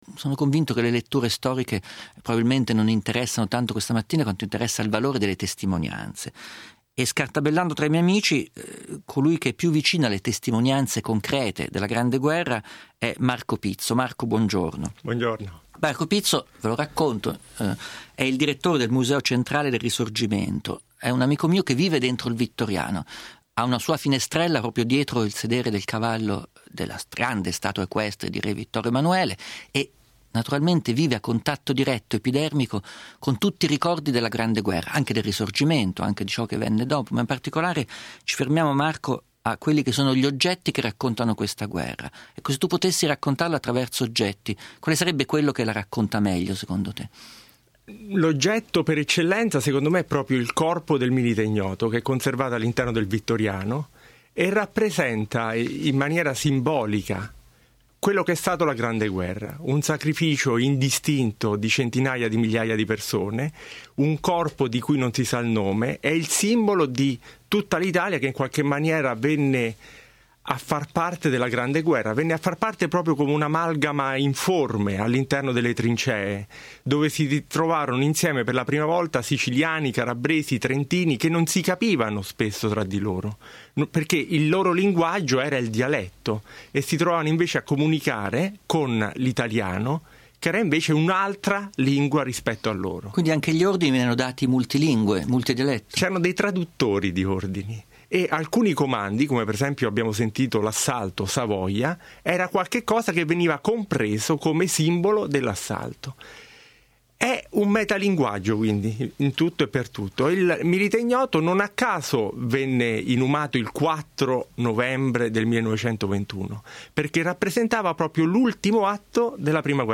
AUDIO: Gianluca Nicoletti legge Stefano Disegni a Melog